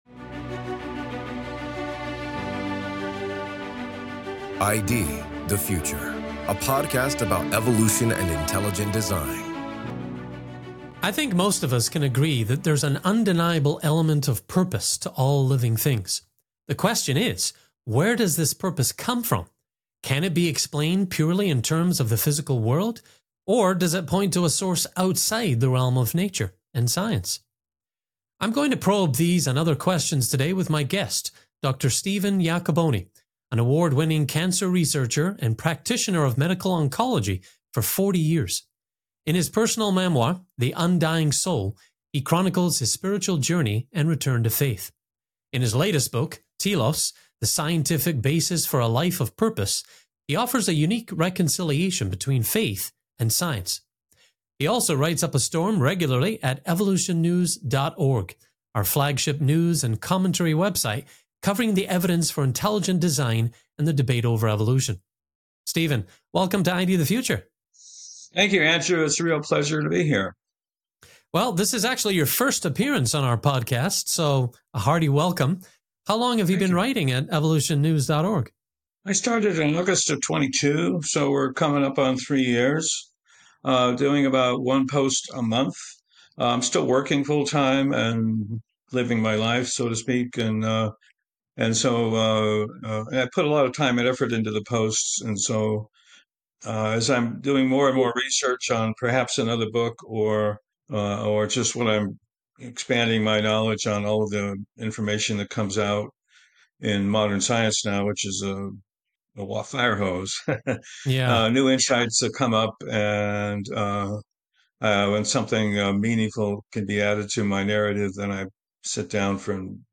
The conversation dives deep into the question of whether this purpose can be explained purely by the physical world, or if it points to a source beyond nature and science.